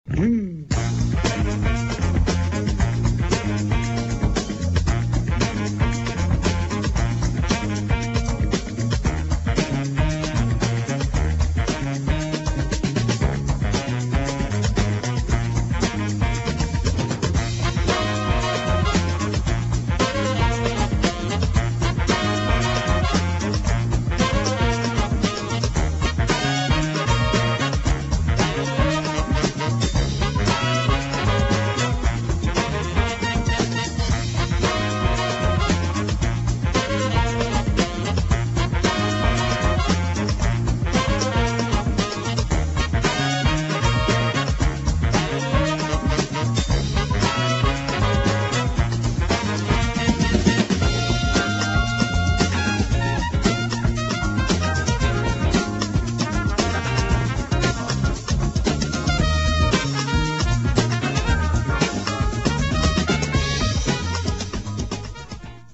[ EASY LISTENING | JAZZ | FUNK ]